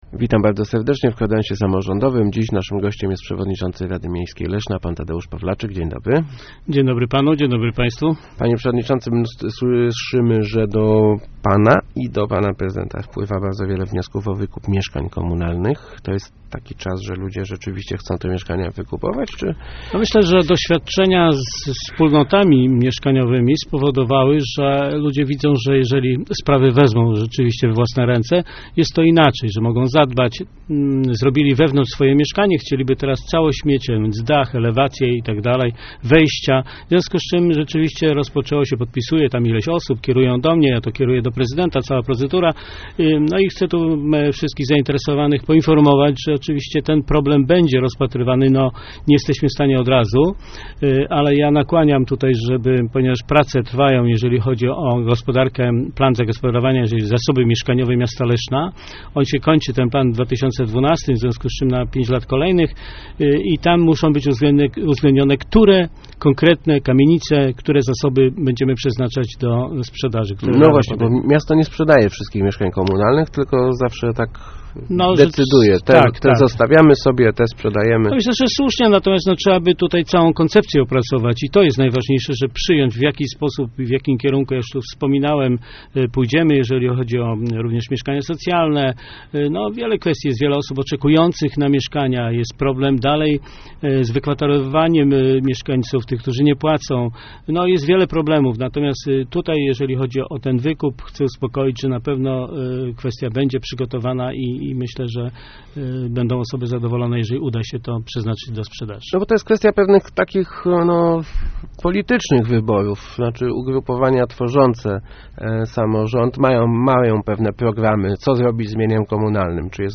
Gościem Kwadransa Samorządowego był przewodniczący RML Tadeusz Pawlaczyk ...